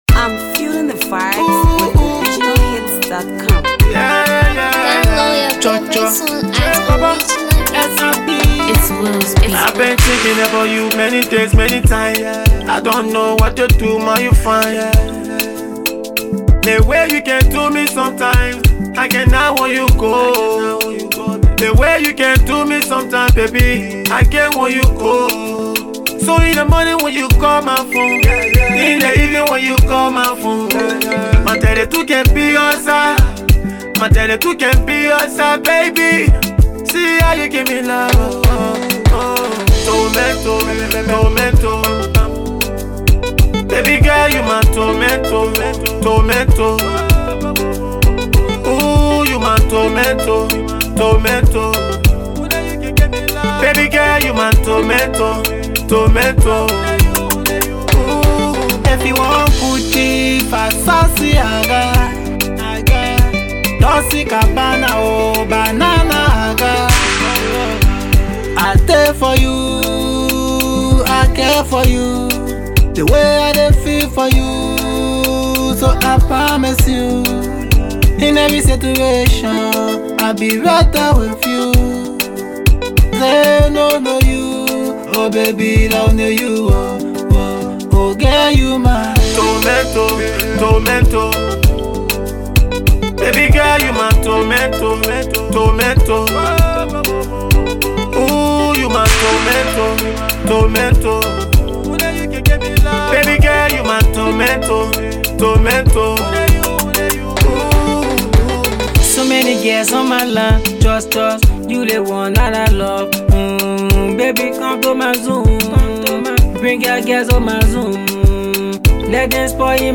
studio jam